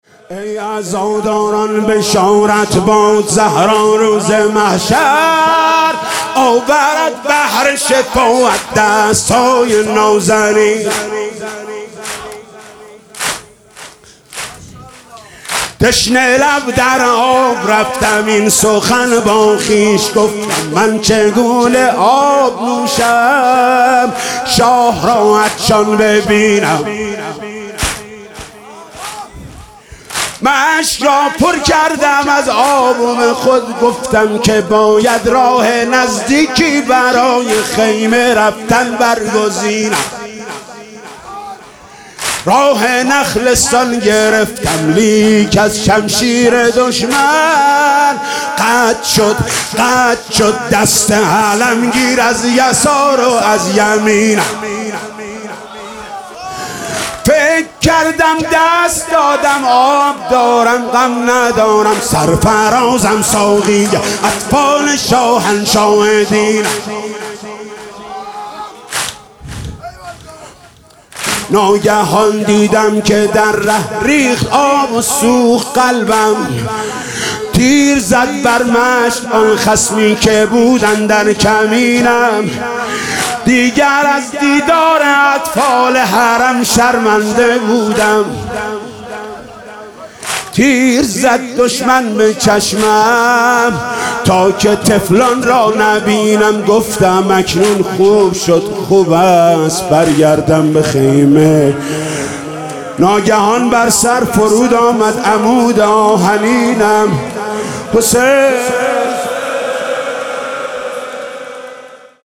«محرم 1396» (شب تاسوعا) واحد: ای عزاداران بشارت باد
«محرم 1396» (شب تاسوعا) واحد: ای عزاداران بشارت باد خطیب: حاج محمود کریمی مدت زمان: 00:01:52